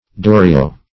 Durio \Du"ri*o\, n. [NL., fr. Malay d?ri thorn.] (Bot.)